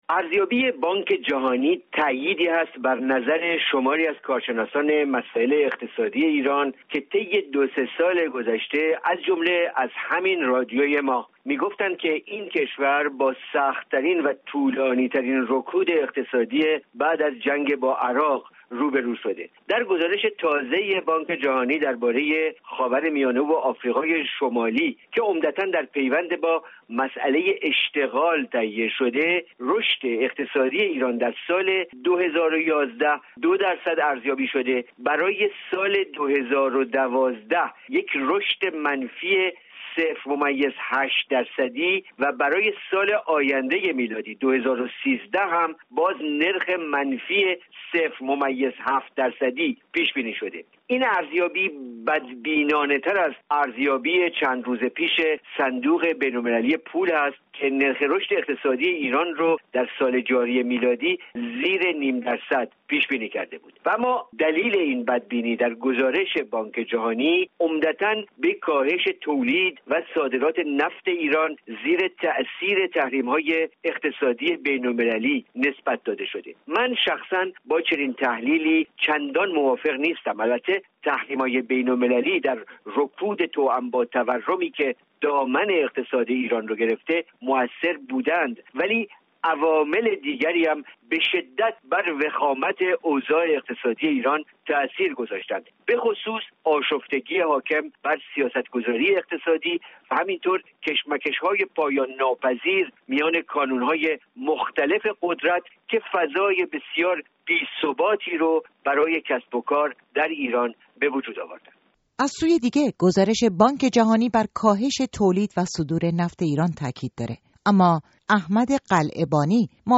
بانک جهانی نرخ رشد اقتصادی ایران را در سال جاری و سال آینده میلادی منفی پیش‌بینی می‌کند. این موضوع گفت‌وگوی اقتصادی امروز رادیو فردا است.